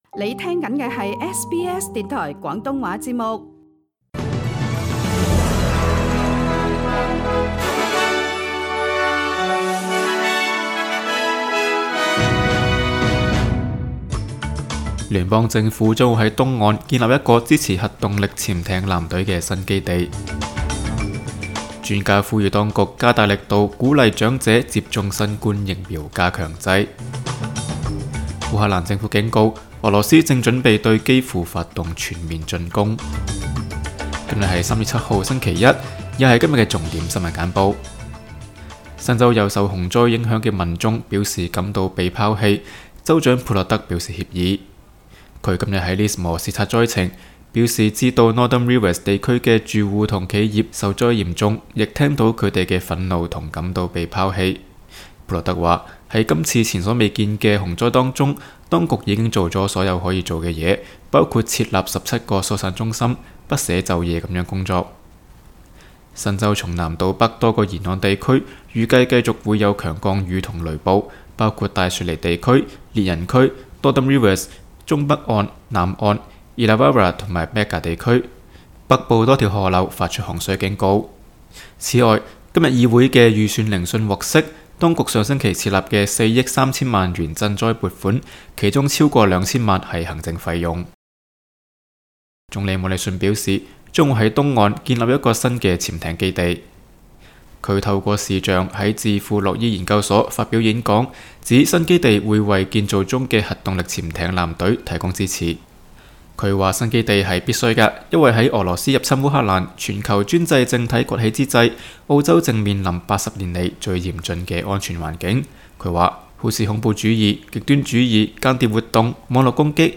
SBS 新闻简报（3月7日）